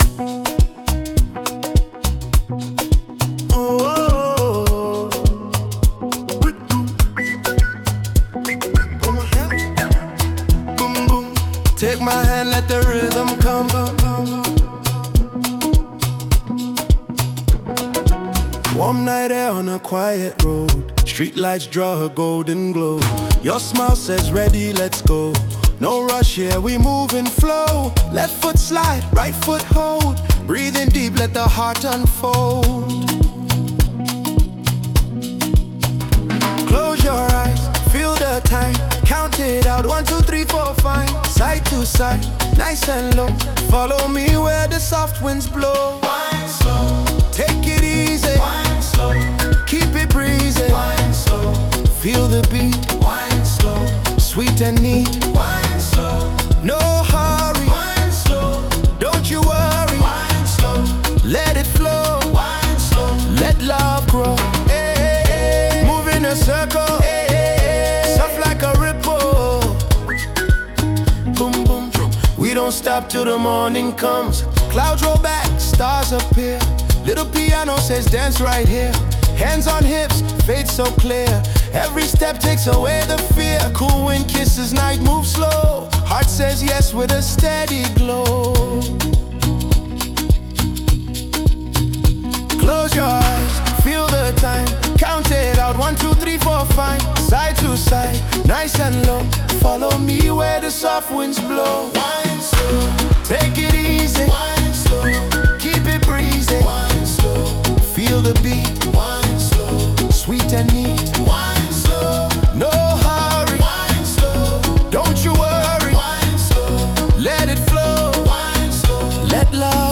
Afrobeats 2025 Non-Explicit